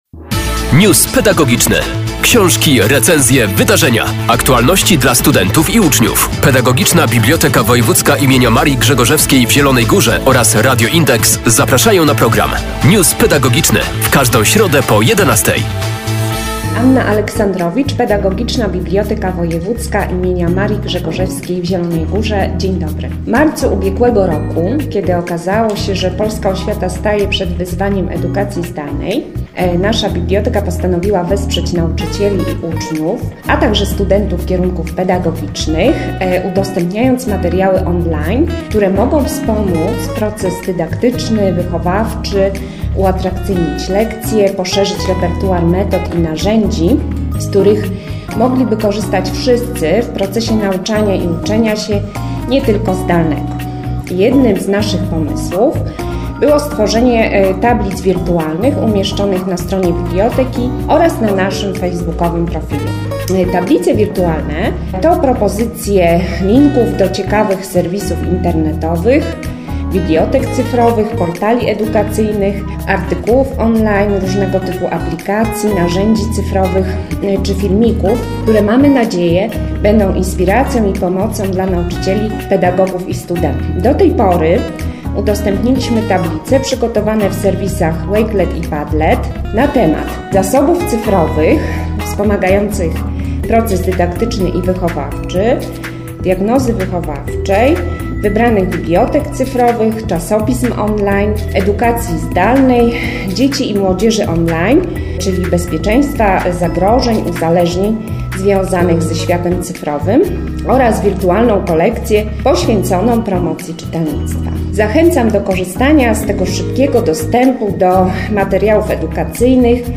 News pedagogiczny.